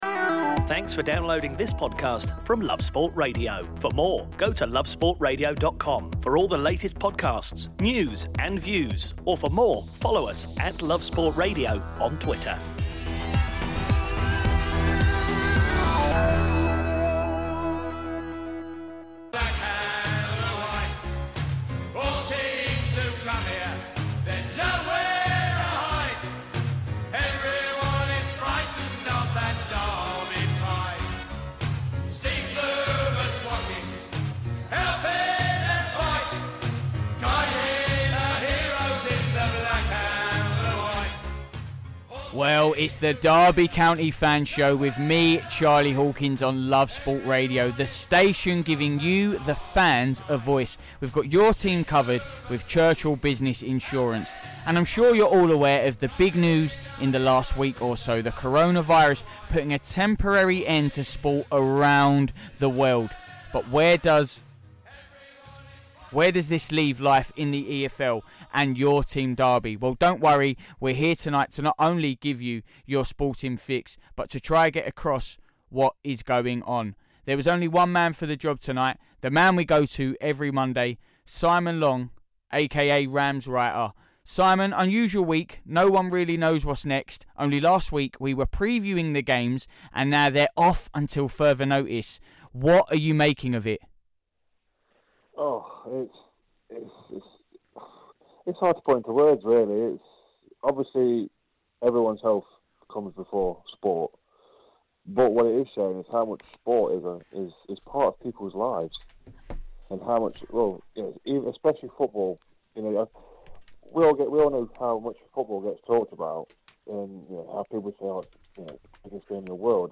a quick chat about the advetn of Coronavirus and how it will affect Derby and the EFL as a whole.